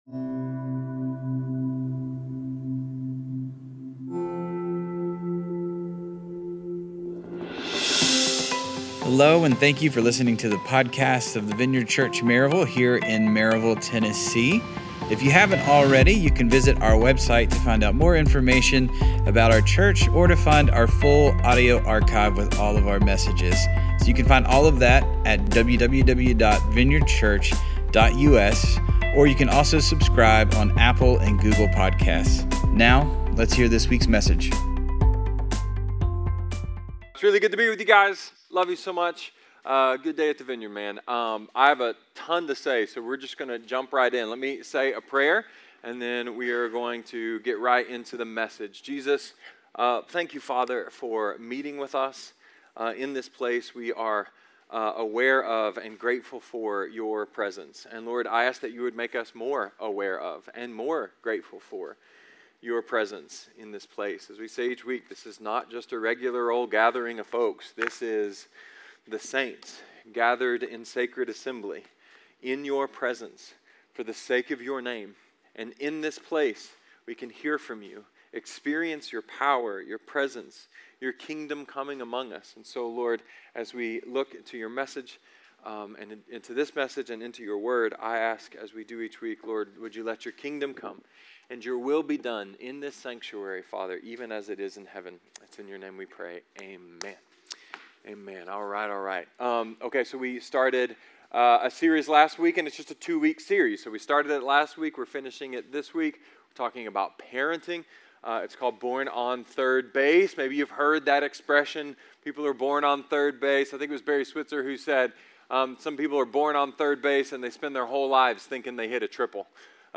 A sermon about opening and closing doors for generations.